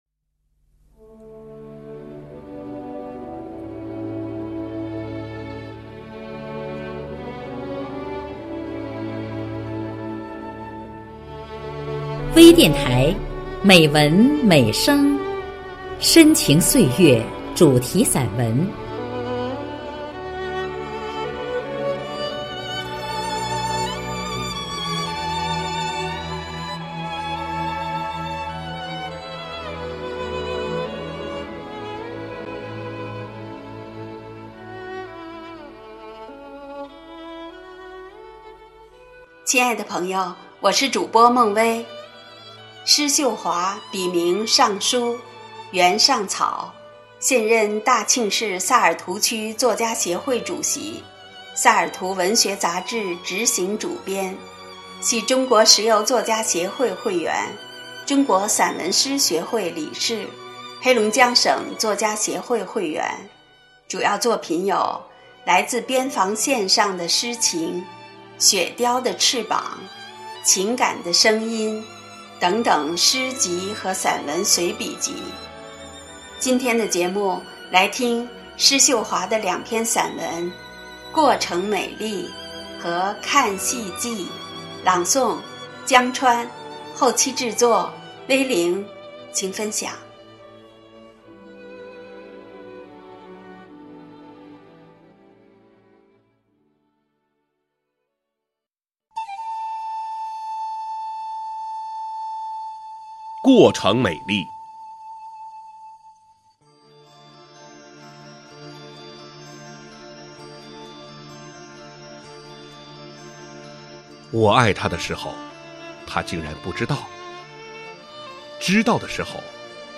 专业诵读